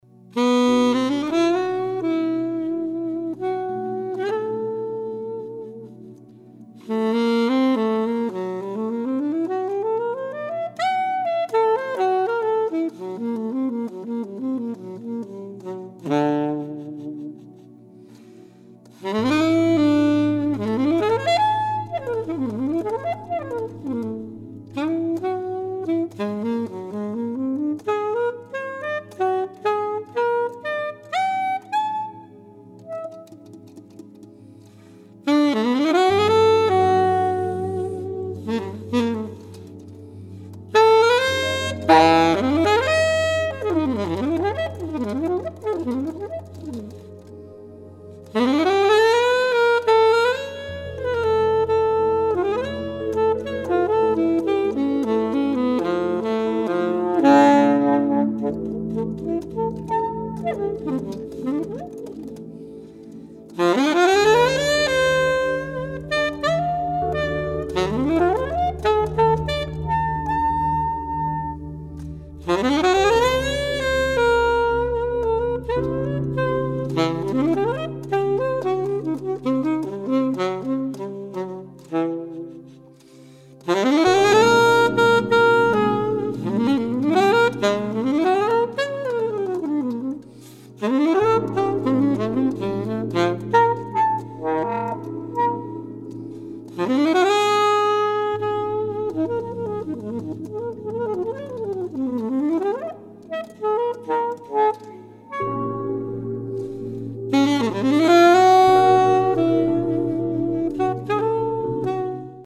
alto and sopranino saxophones
piano and keyboards
double bass
drums